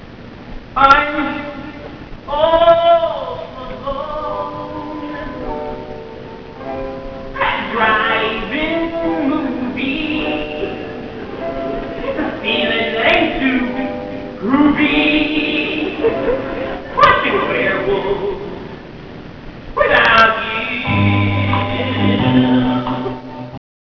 Live Sound Clips from "Grease'